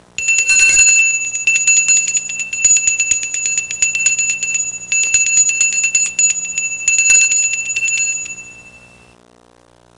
Dinnertime Sound Effect
dinnertime.mp3